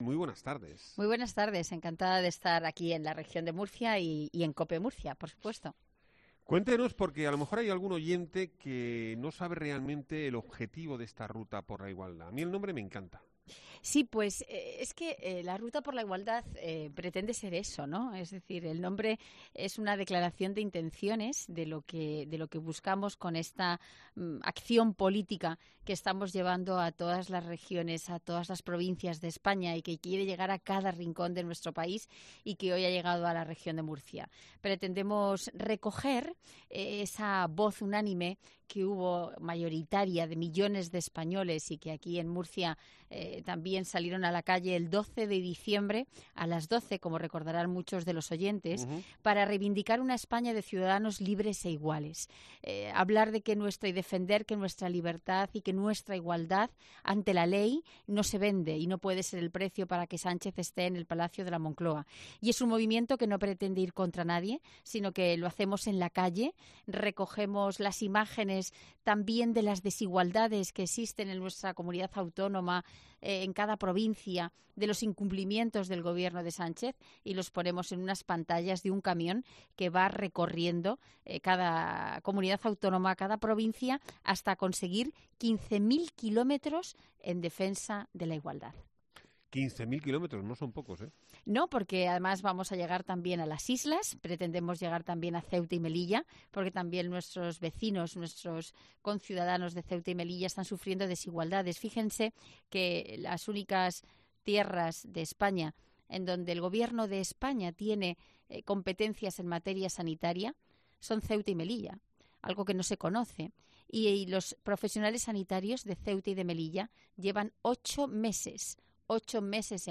Carmen Fúnez, vicesecretaria de Organización del PP
Lo ha dicho en Murcia, donde este martes ha llevado la campaña “Ruta por la igualdad”, surgida a raíz de las movilizaciones del pasado 12 de noviembre de 2023 en contra de la ley de Amnistía, y con la que el PP está recorriendo toda la geografía Española para mostrar su rechazo a esa norma.